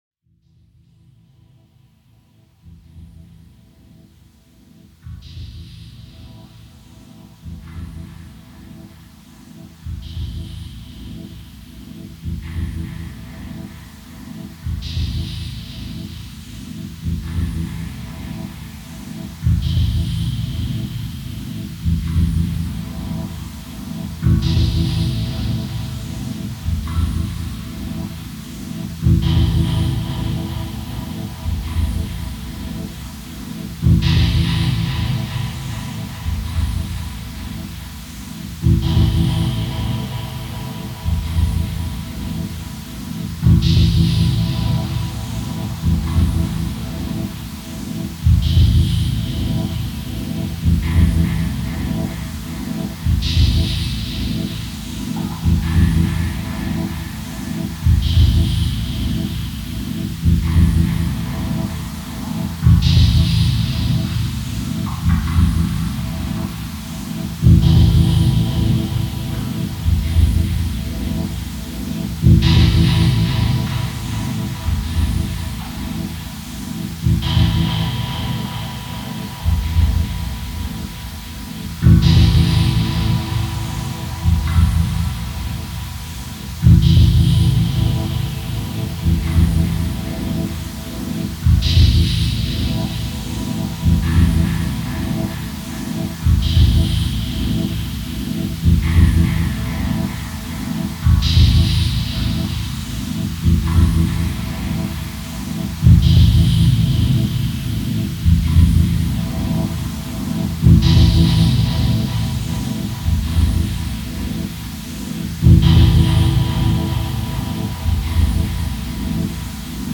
Genre: Dub Techno/Ambient.